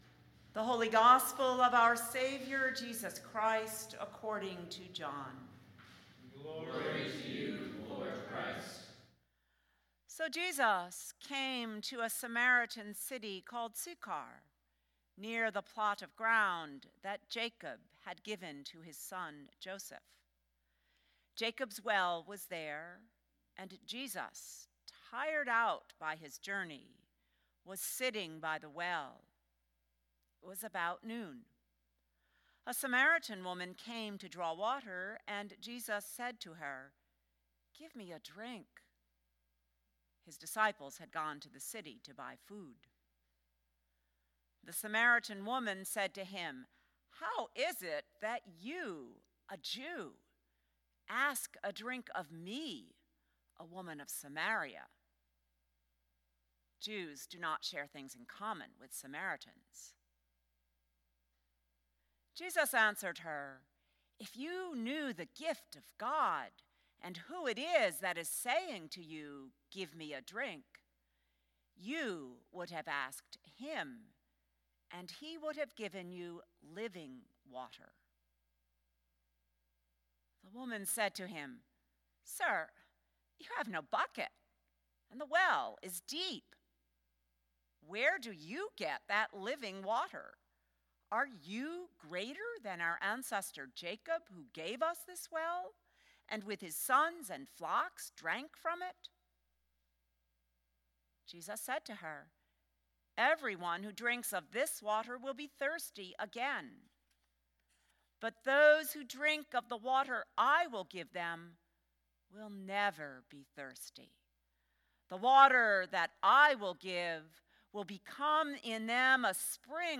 Gospel Reading: John 4:5-42